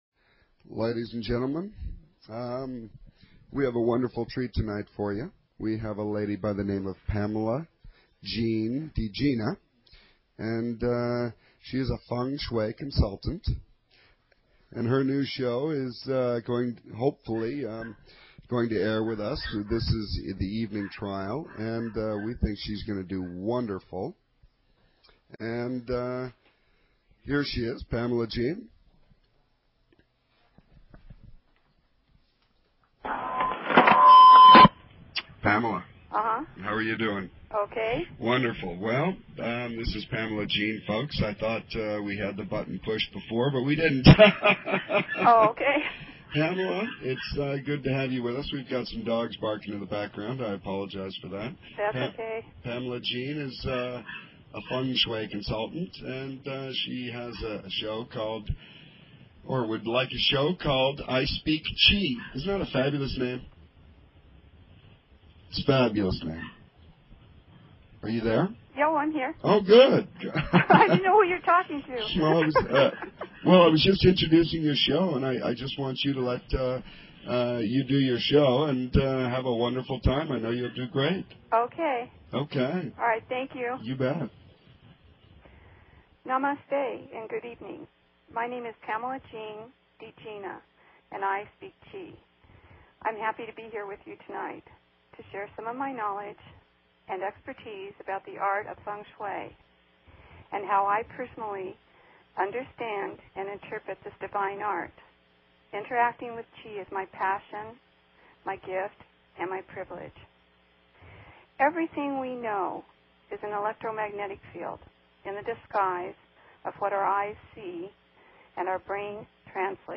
Evening Trial, a talk show on BBS Radio!